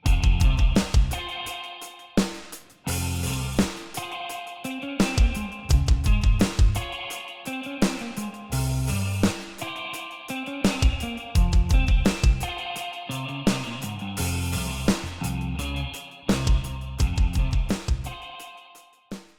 SPRING – pays homage to the classic Fender® 6G15 tube spring reverb
Long Spring Reverb
Pico-Oceans-3-VerbMulti-function-Reverb-Long-Spring.mp3